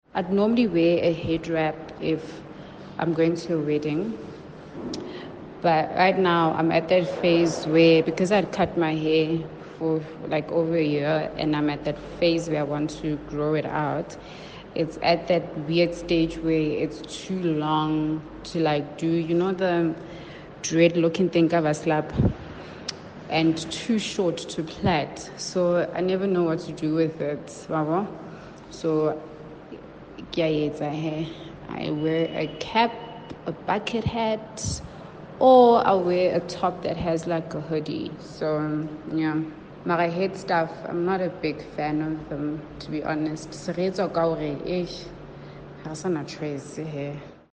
Listen to Kaya Drive listeners sharing what a headwrap means to them: